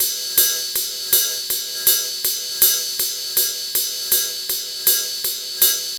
Ride 04.wav